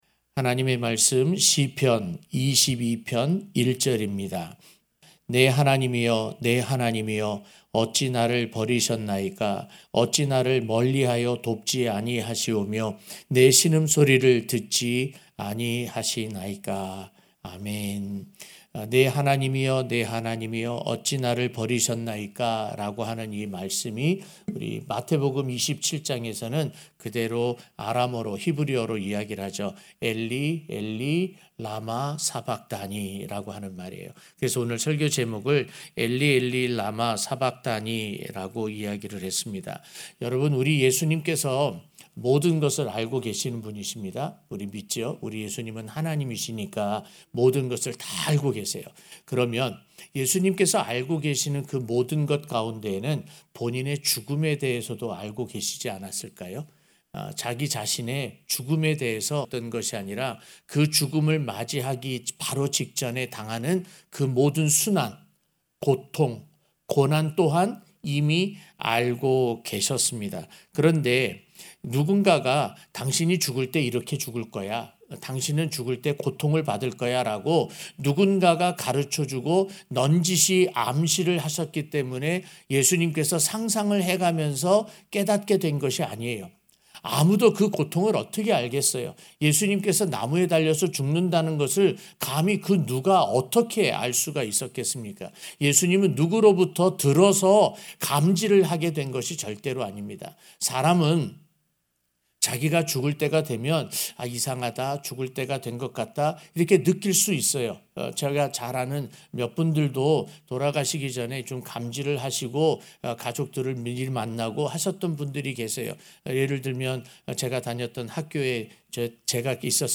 고난주간설교 4-엘리 엘리 라마 사박다니 (시 22:1)